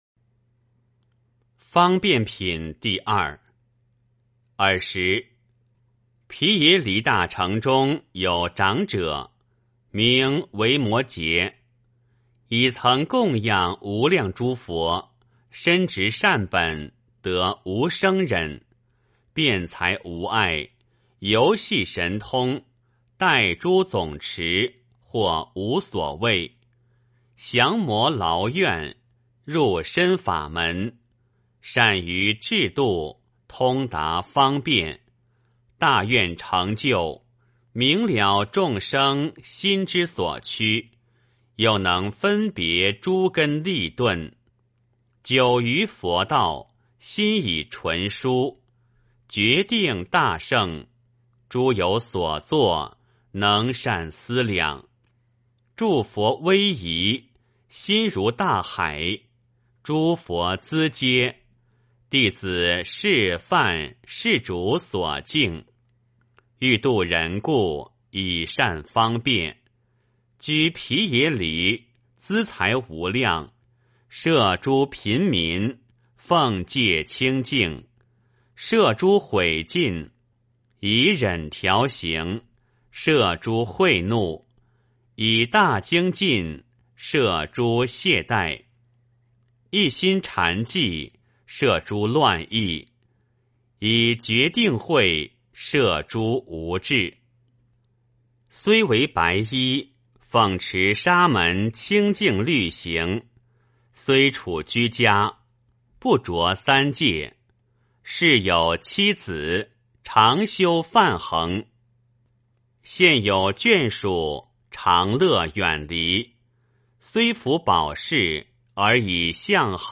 维摩诘经-02-念诵 - 诵经 - 云佛论坛